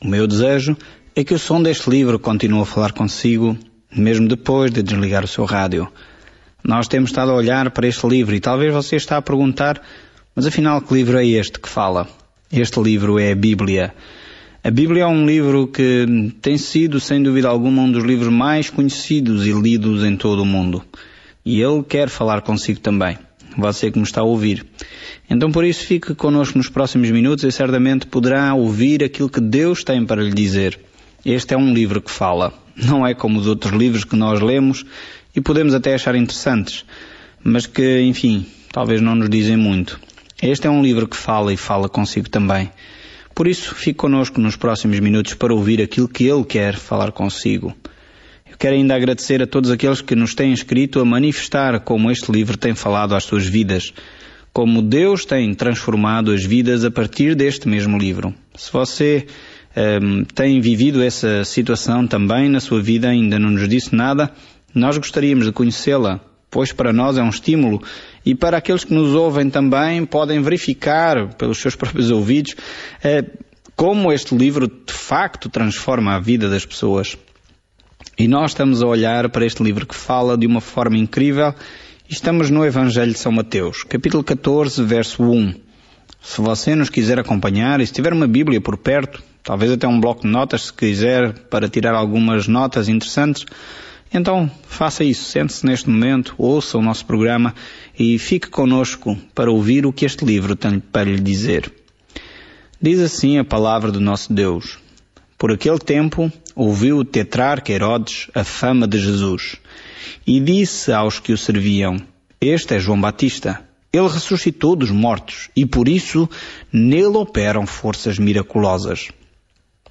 Escritura MATEUS 14:1-36 Dia 23 Iniciar este Plano Dia 25 Sobre este plano Mateus prova aos leitores judeus as boas novas de que Jesus é o Messias, mostrando como Sua vida e ministério cumpriram a profecia do Antigo Testamento. Viaje diariamente por Mateus enquanto ouve o estudo em áudio e lê versículos selecionados da palavra de Deus.